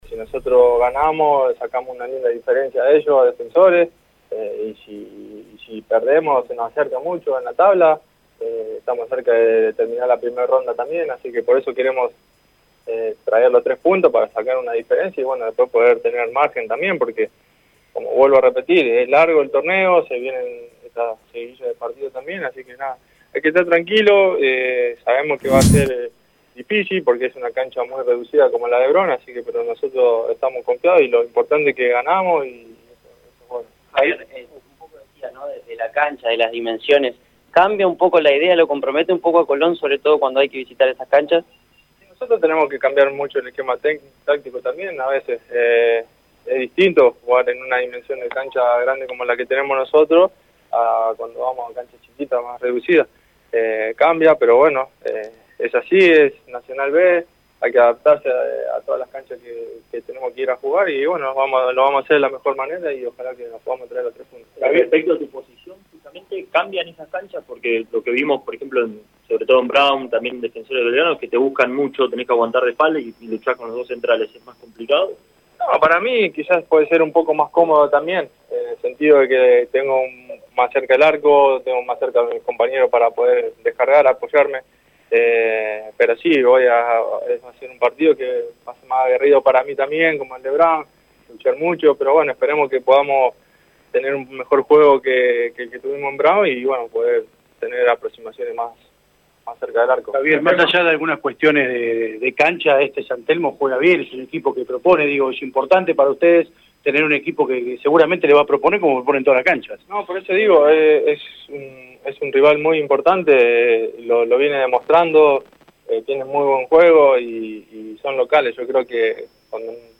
Javier Toledo primero y Sebastián Prediger después, fueron los dos futbolistas de Colón que brindaron una conferencia de prensa luego de la práctica de esta mañana.